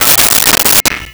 Pot Lid 01
Pot Lid 01.wav